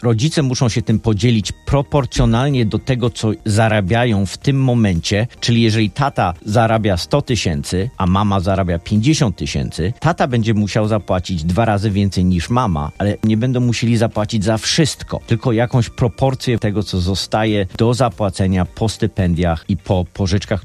W studiu Radia Deon Chicago